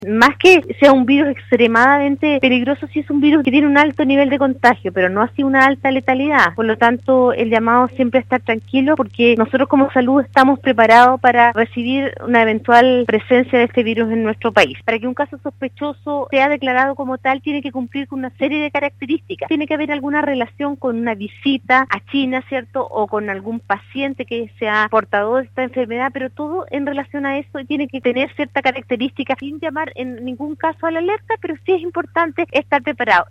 En conversación con Radio Sago, Marcela Cárdenas, seremi (s) salud en la región de Los Lagos se refirió a la enfermedad del Coronavirus o también conocida como neumonía de Wuhan que ha generado alarma a nivel mundial tras haber cobrado la vida de más de 200 personas en China.